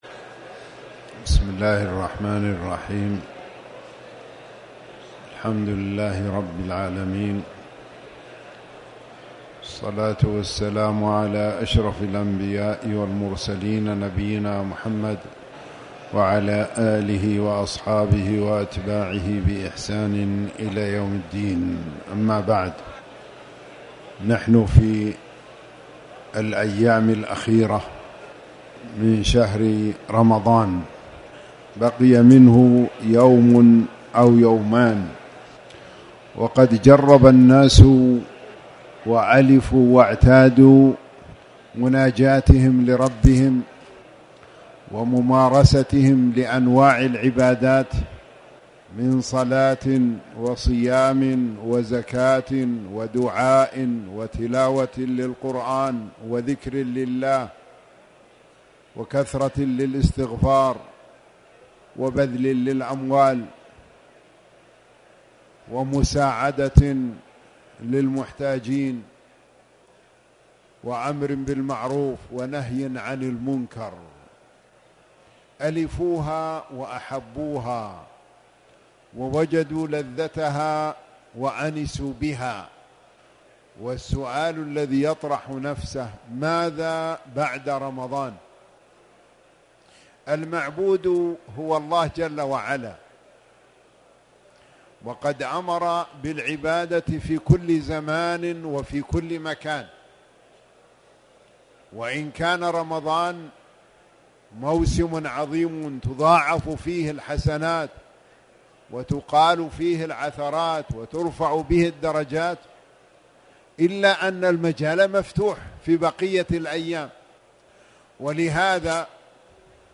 تاريخ النشر ٢٨ رمضان ١٤٣٩ هـ المكان: المسجد الحرام الشيخ